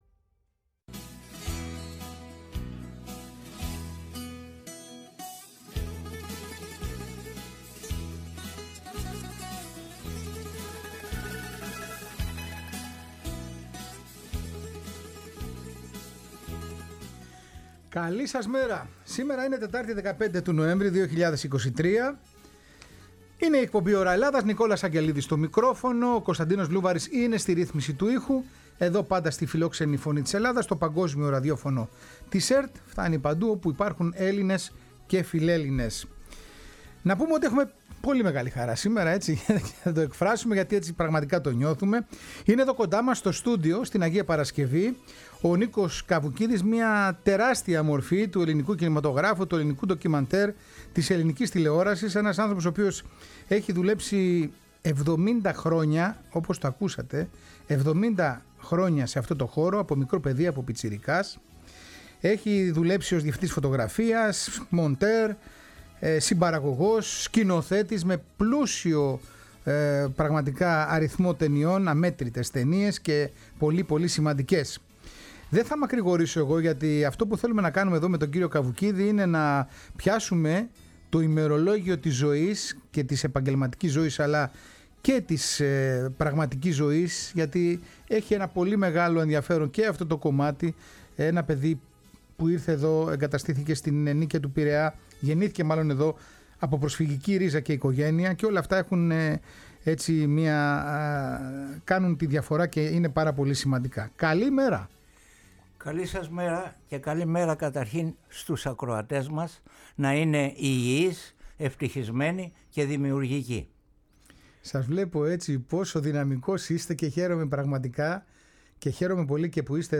Στο επίκεντρο της ζωντανής κουβέντας στο στούντιο της Φωνής της Ελλάδας η ζωή και το έργο του μεγάλου κινηματογραφιστή, ο οποίος μεταξύ άλλων έχει γυρίσει και το ντοκιμαντέρ «Μαρτυρίες 1967-1974» που είναι ιδιαίτερα επίκαιρο στις μέρες μας.
Συνεντεύξεις